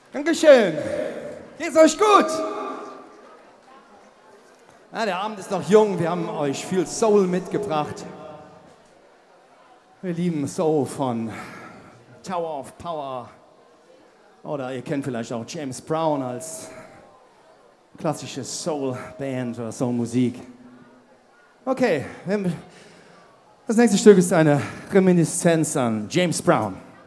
04 - Ansage.mp3